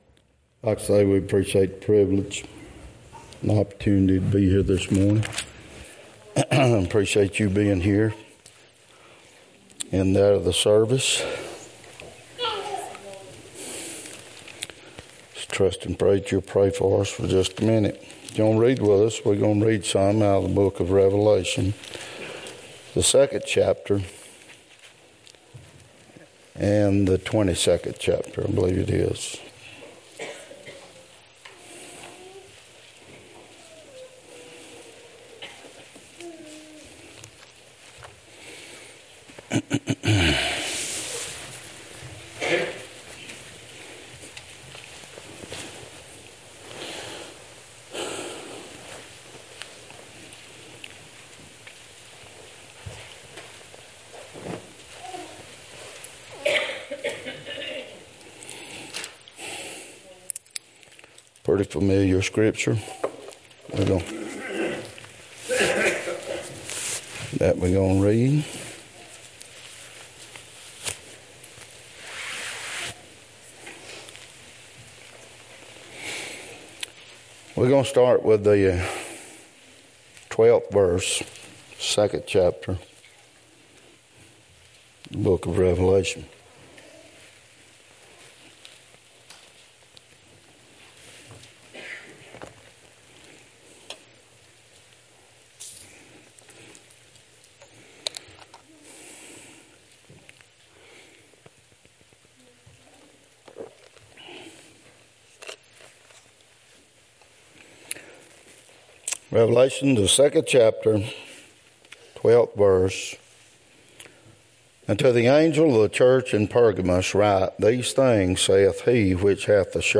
22:16-19 Service Type: Sunday Topics